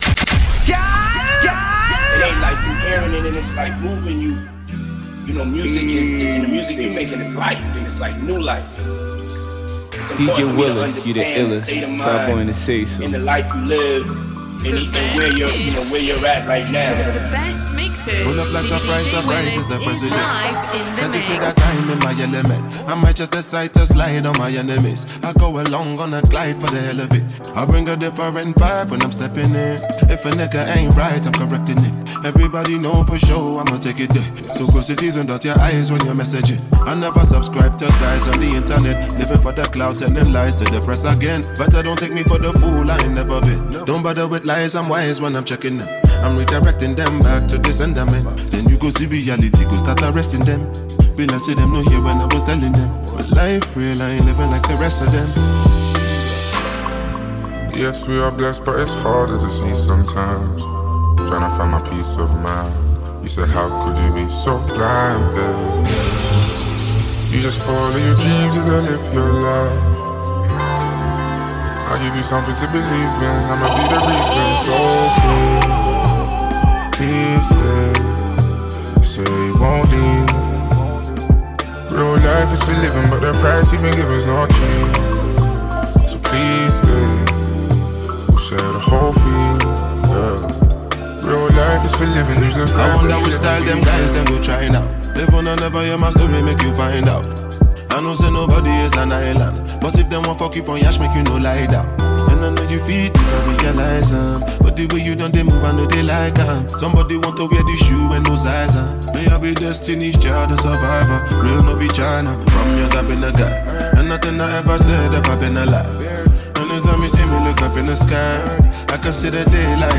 this DJ mixtape has all the best Afrobeat